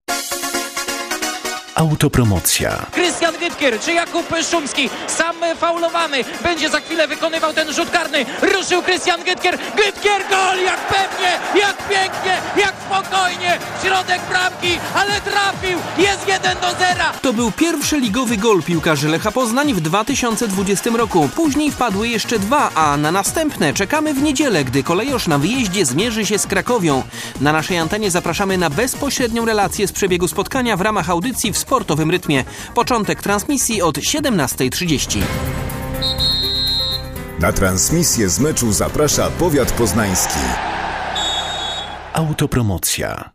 Ale też nasze klipy zapowiadające mecze Lecha są przygotowane z dużym poczuciem humory, wykorzystując gwarę czy też różnego rodzaju motywy stadionowe.